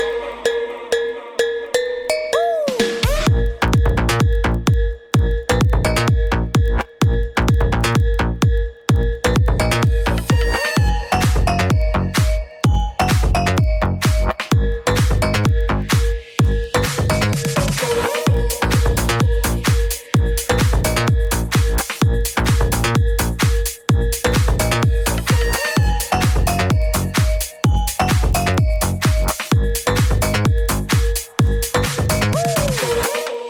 • Качество: 203, Stereo
Club House
без слов
энергичные
клубняк
Известный трек с басом